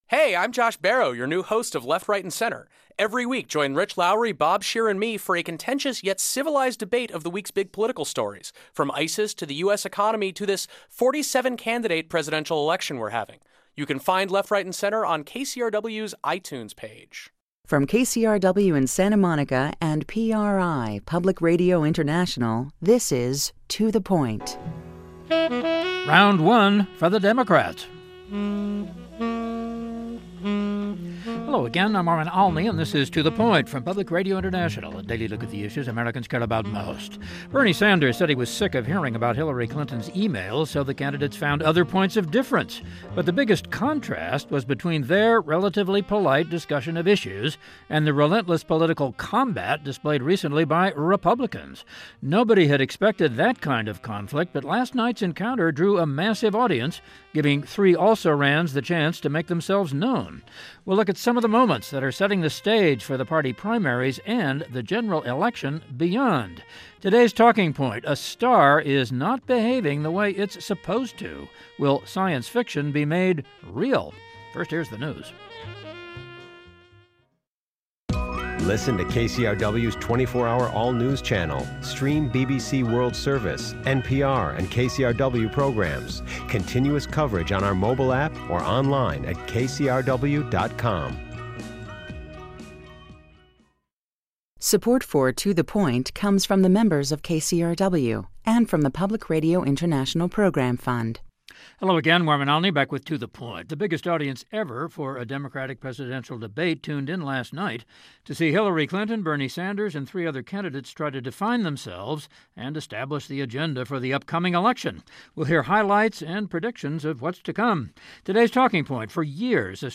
The biggest audience ever for a Democratic presidential debate tuned in last night to see Hillary Clinton, Bernie Sanders and three other candidates try to define themselves and establish the agenda for the upcoming campaign. We hear excerpts and look at some of the moments that are setting the stage for the party primaries — and the general election beyond.